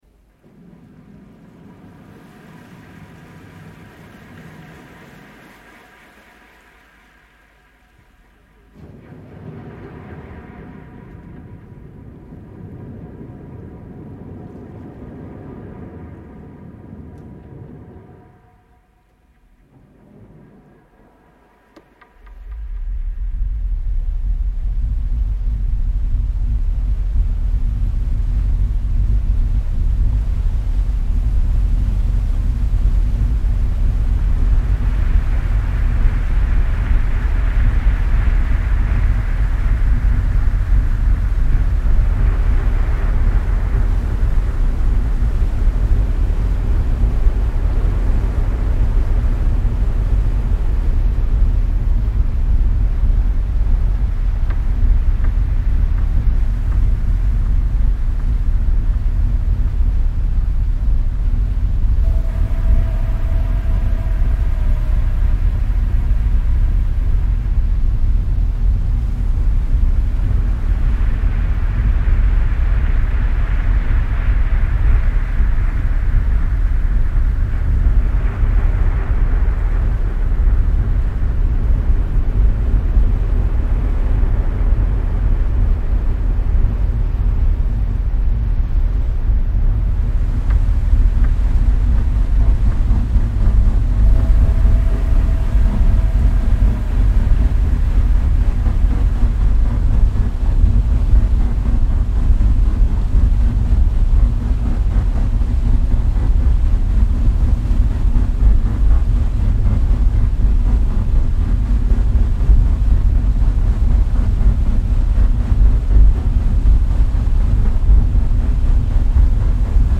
Vaporetto ride in Chioggia reimagined